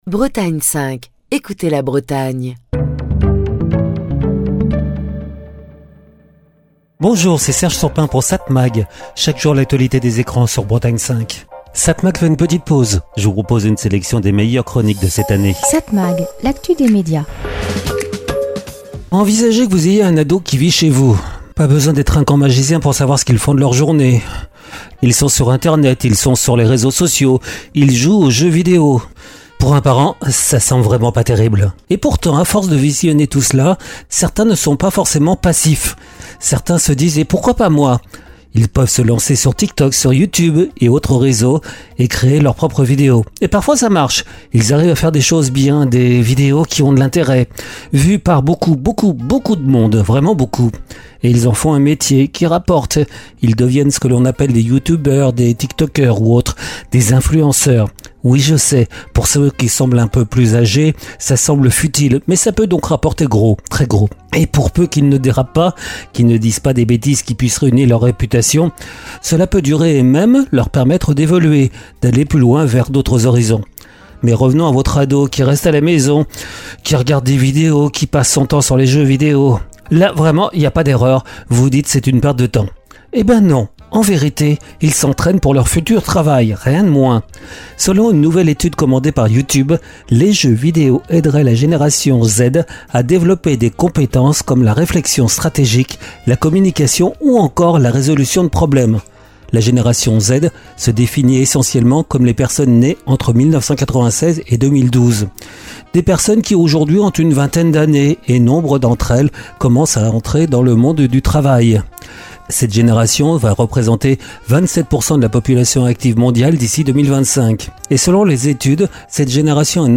Chronique du 22 juillet 2025.